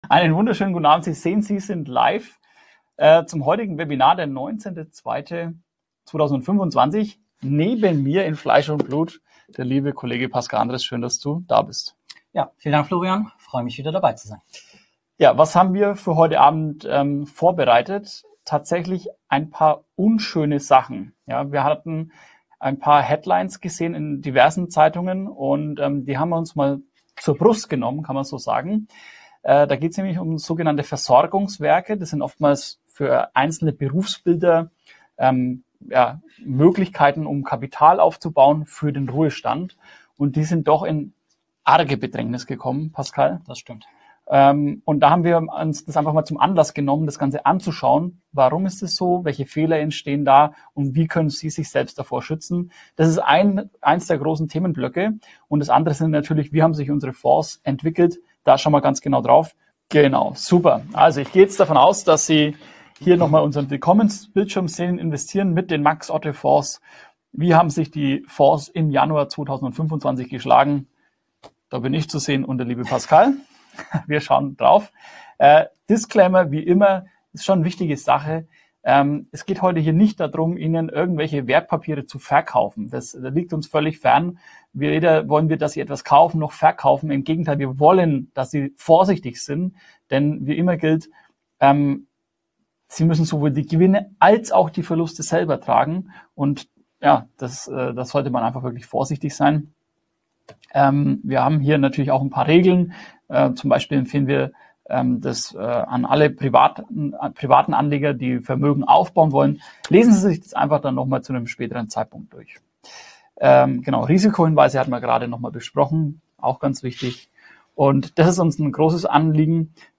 Webinar